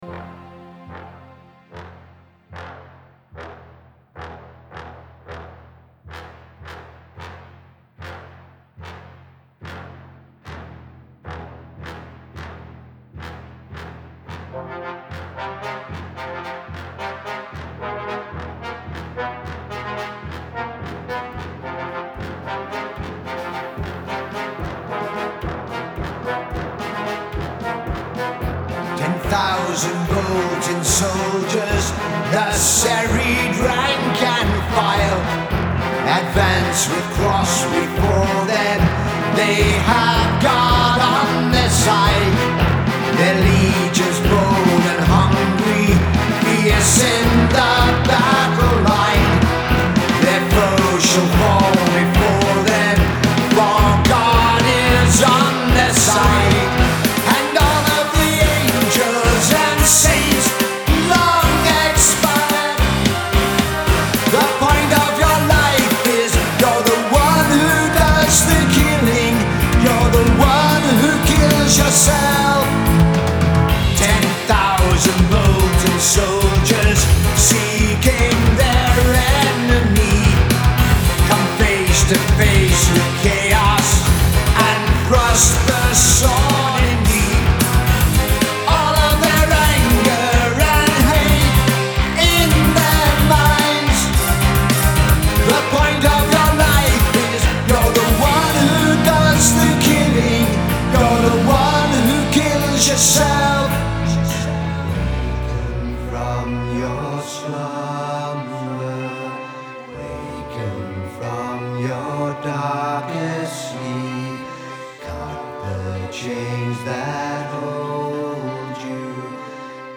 keyboards/drums
bass/lead vocals
guitars/backing vocals
It's a dark album that draws you in, and holds you there…